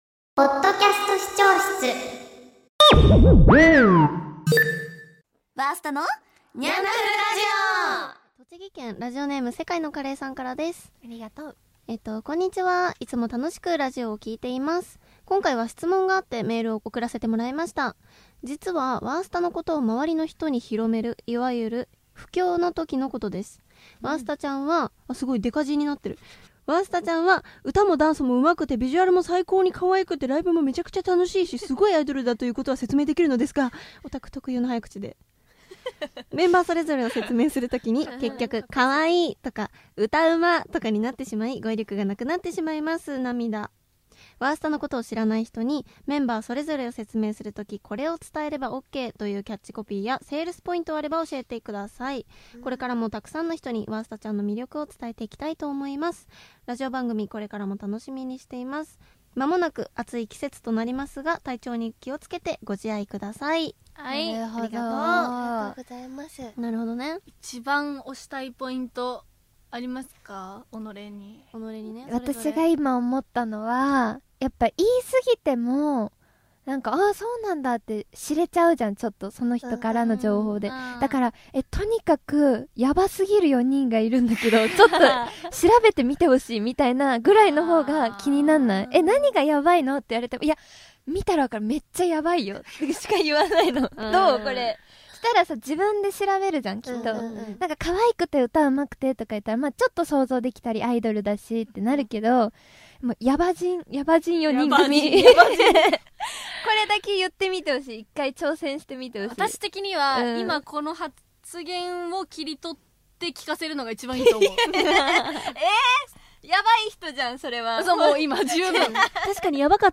人気アイドルグループ「わーすた」の4人が最近の出来事について話したり、様々な企画コーナーにチャレンジしたりする番組です。
メンバー変わらず仲良しなだからこそだせる、わちゃわちゃ感満載の放送をぜひお楽しみください。